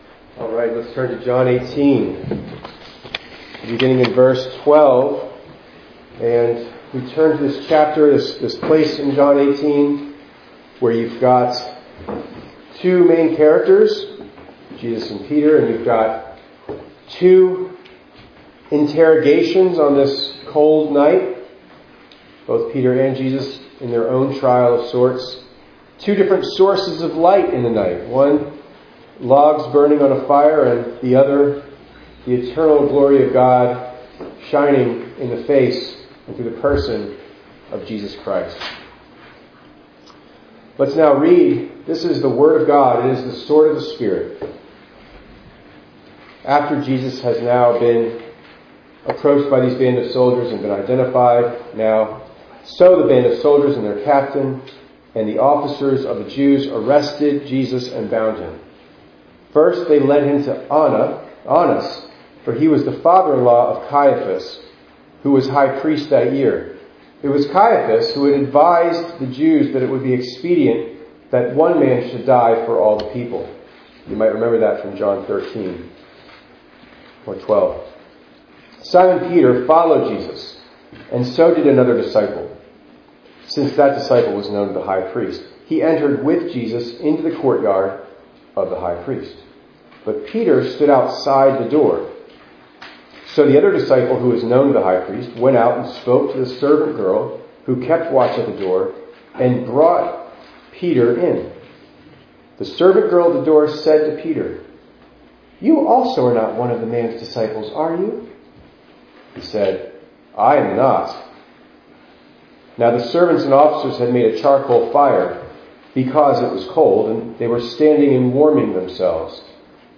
2_25_24_ENG_Sermon.mp3